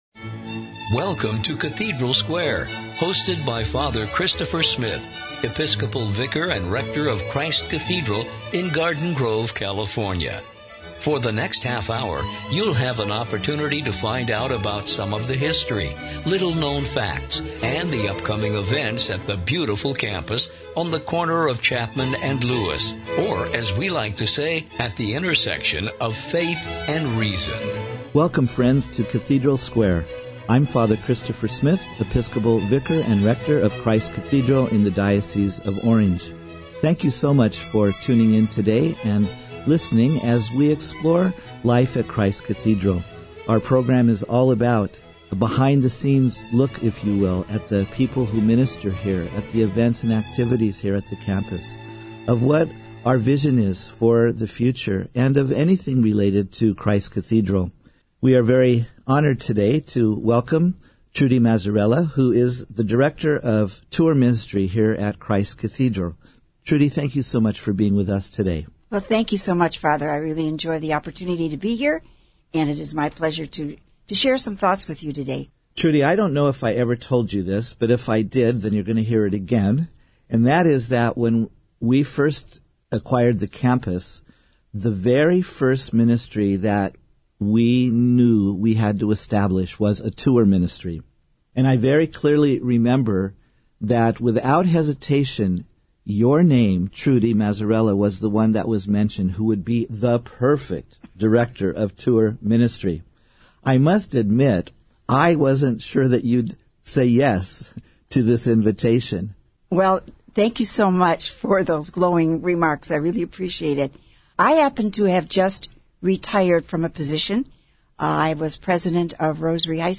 Listen in to this very engaging discussion; and, share it with a friend!